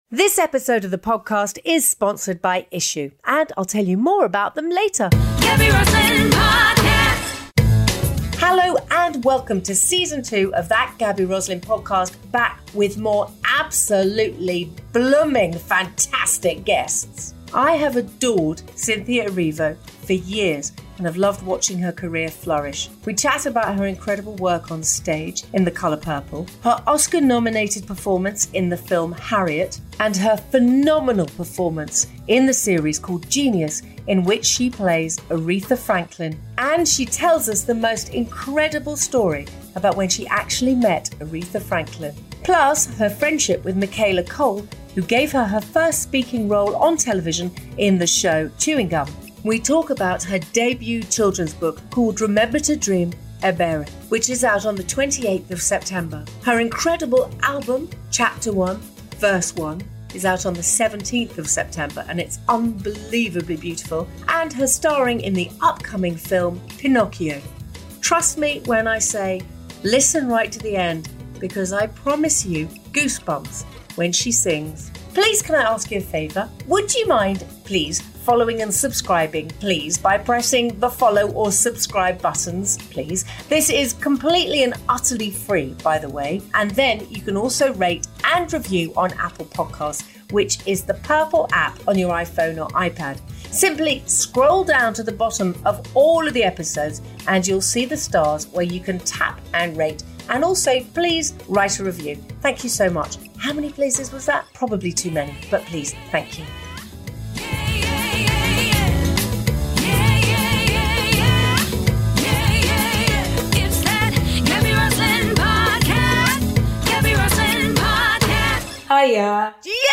In this episode Gaby chats with actress and singer Cynthia Erivo.
Do not miss her sensational singing right at the end of this episode!